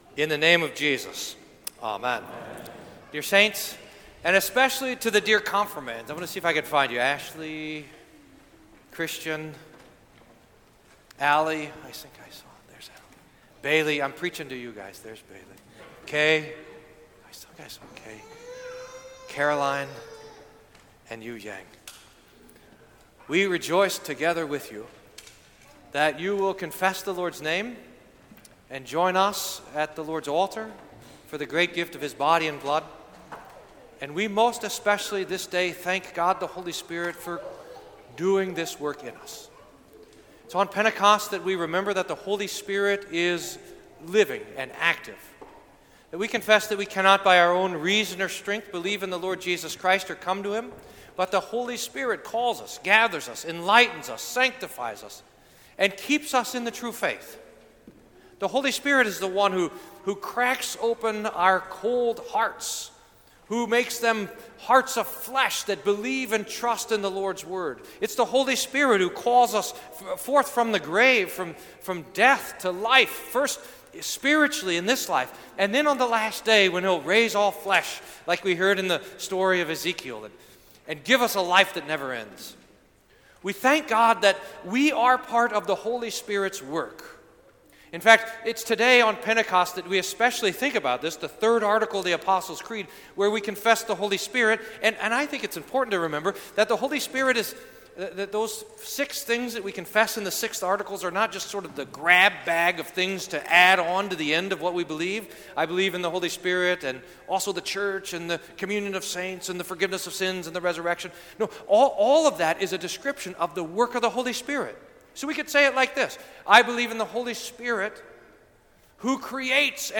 Sermon for Pentecost